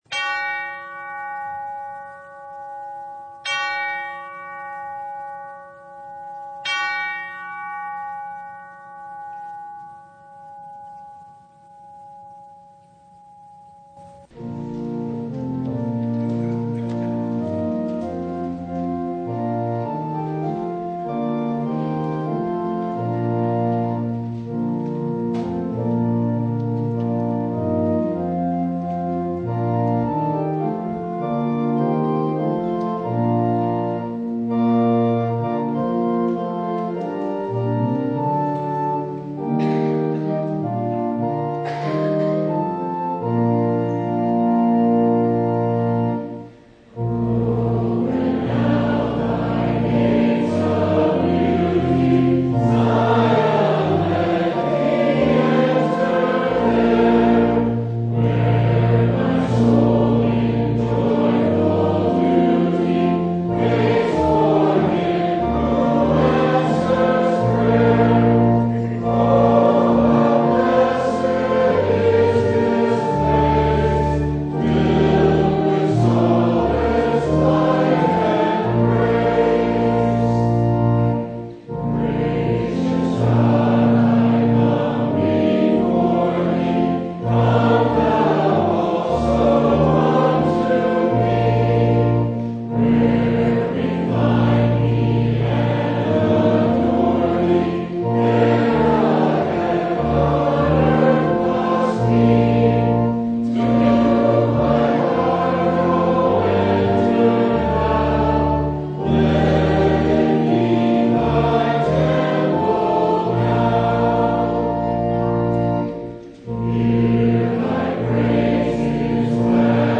Matthew 13:1-9,18-23 Service Type: Sunday A sower went out to sow .